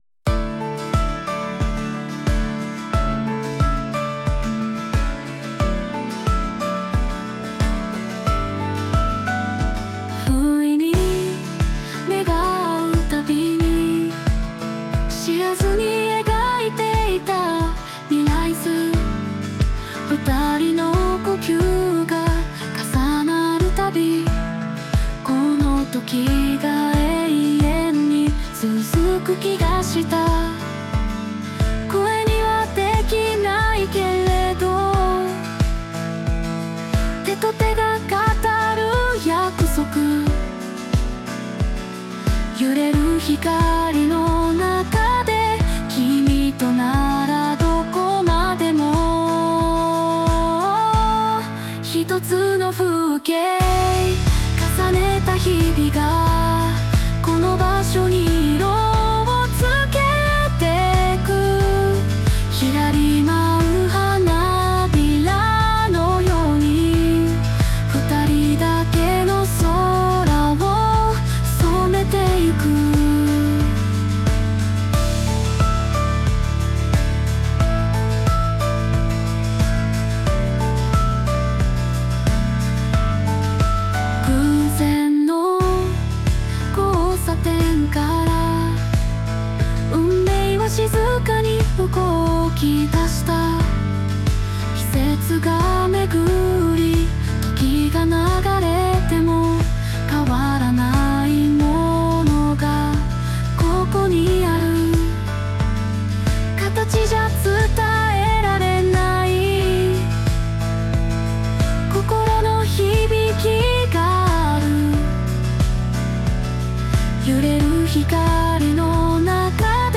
邦楽女性ボーカル著作権フリーBGM ボーカル
著作権フリーオリジナルBGMです。
女性ボーカル（邦楽・日本語）曲です。